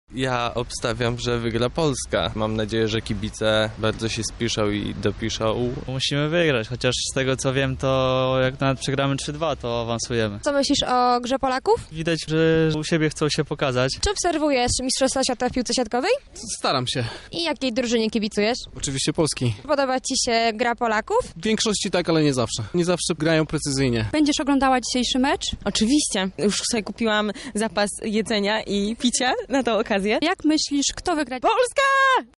Zawodnikom kibicują lublinianie.
sonda siatkówka